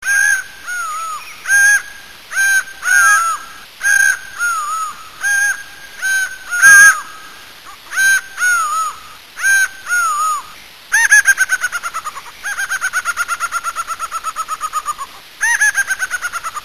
junglebirds.mp3